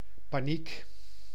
Ääntäminen
Synonyymit peur fuite frayeur Ääntäminen France: IPA: /pa.nik/ Haettu sana löytyi näillä lähdekielillä: ranska Käännös Ääninäyte Substantiivit 1. paniek {f} Suku: f .